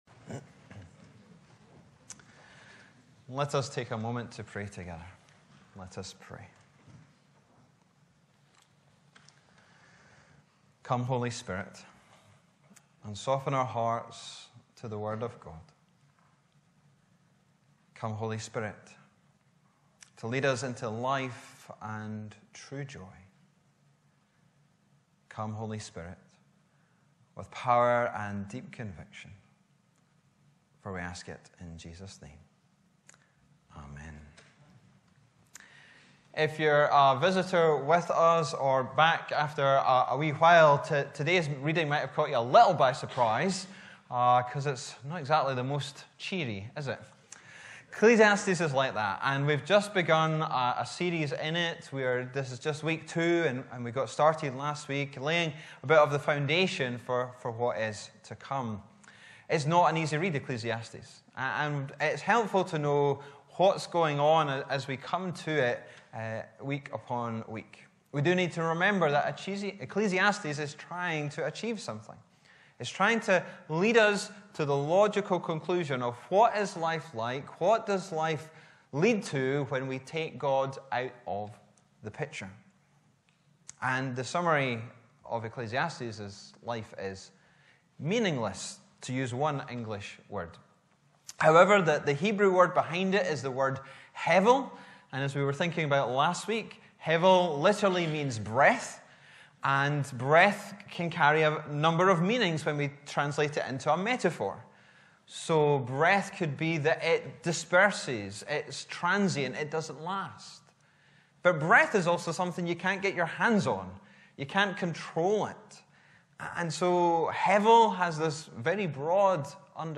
Bible references: Ecclesiastes 2:1-11 & 17-23 Location: Brightons Parish Church Show sermon text Sermon keypoints: - What validates your life?